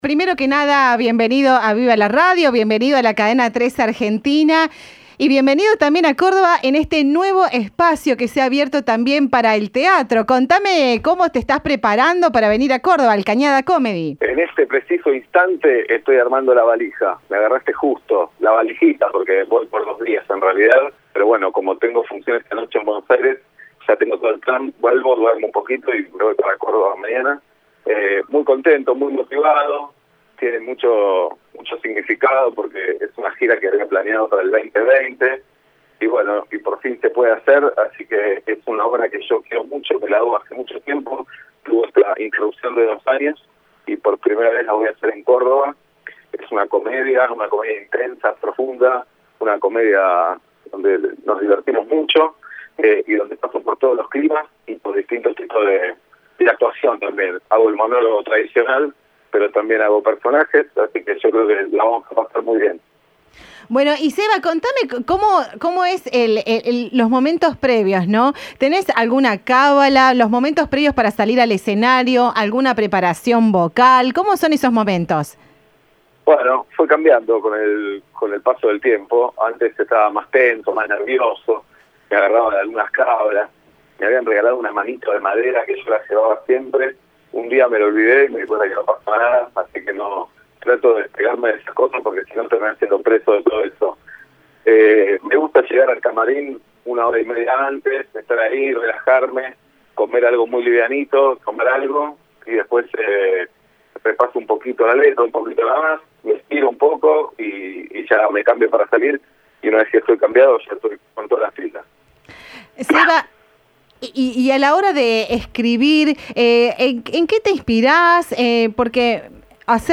El humorista llega a la capital provincial para presentar su unipersonal en el recientemente inaugurado Cañada Comedy. En diálogo con Cadena 3, también habló de la nueva temporada de "Casi feliz".